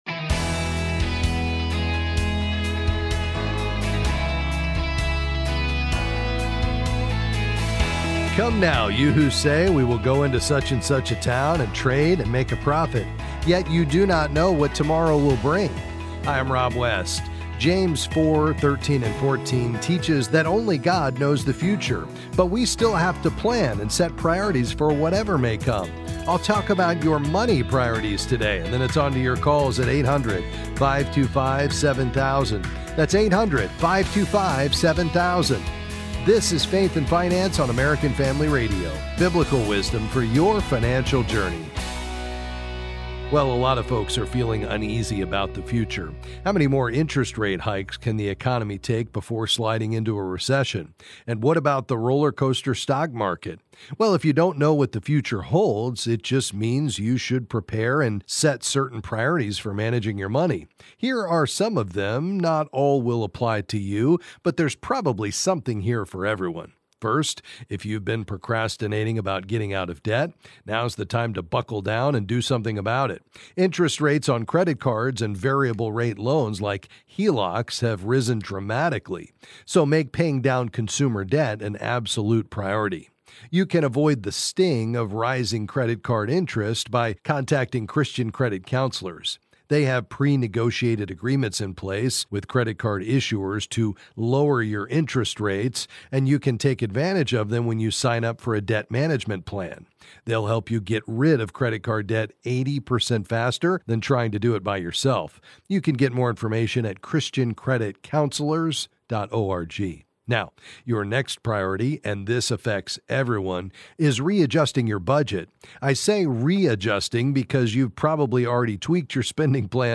Then he’ll answer your calls on various financial topics.